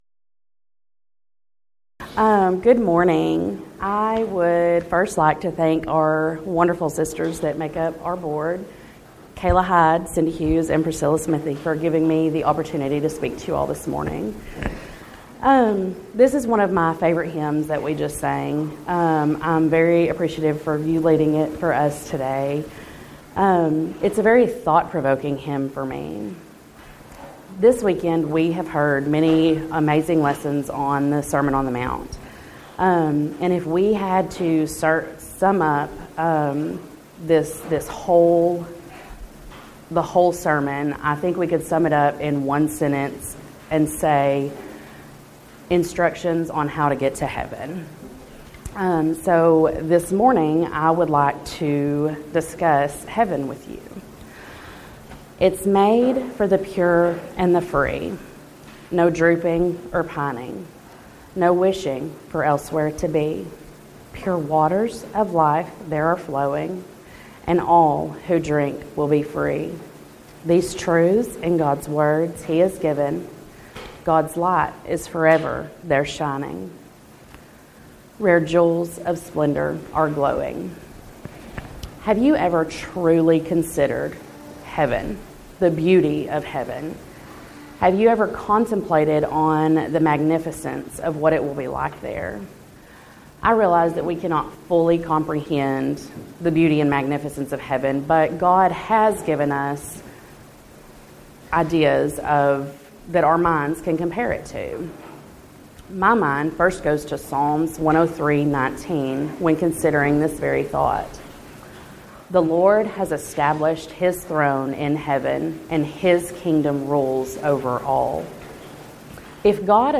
Title: Saturday Morning Devotional
Event: 10th Annual Texas Ladies in Christ Retreat Theme/Title: Righteousness Overviewed